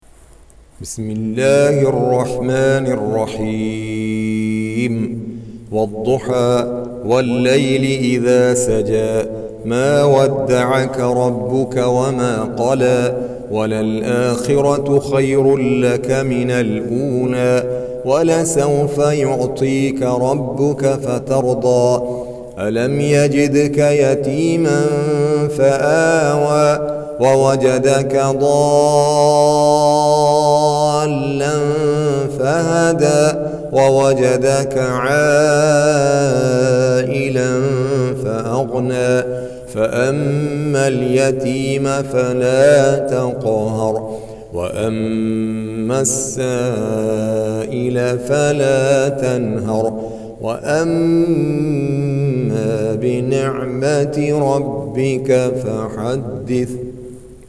Surah Ad-Duha سورة الضحى Audio Quran Tarteel Recitation
EsinIslam Audio Quran Recitations Tajweed, Tarteel And Taaleem.